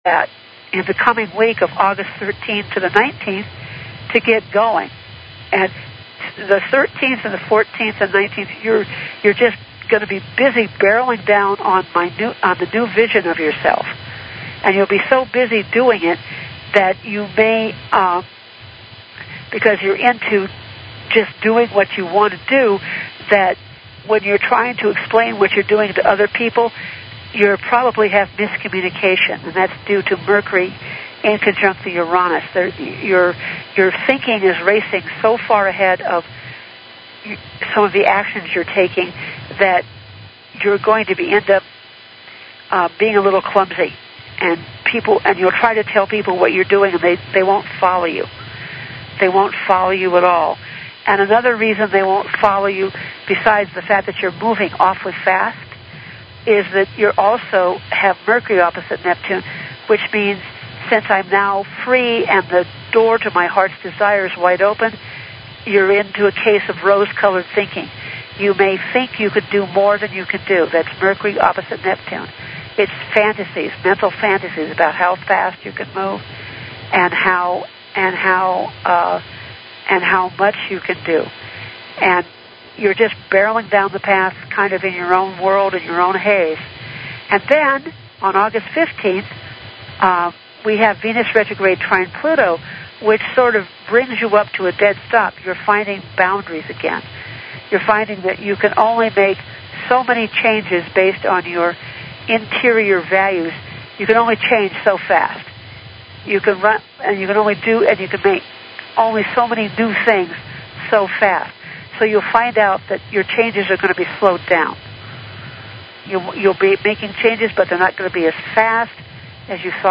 Talk Show Episode, Audio Podcast, Talk_Truth_Radio and Courtesy of BBS Radio on , show guests , about , categorized as